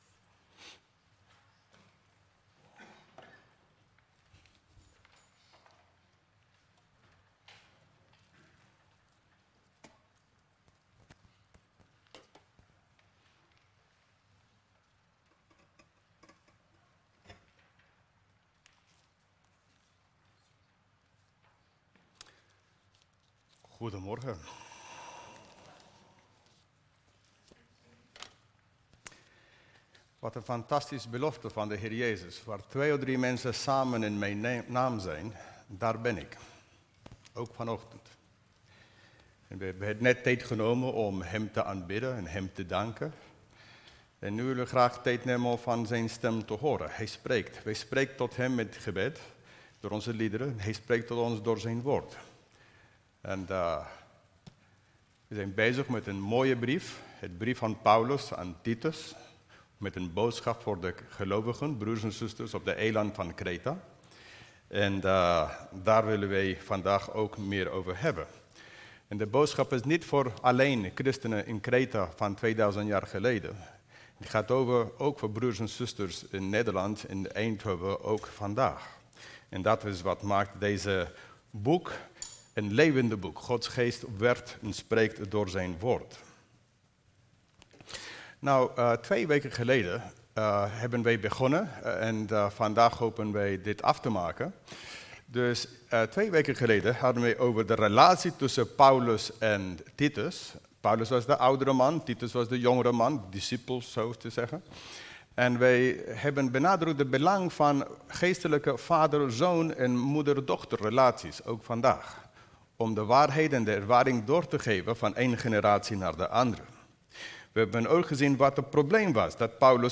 Toespraak 22 augustus: brief aan Titus (deel 2) - De Bron Eindhoven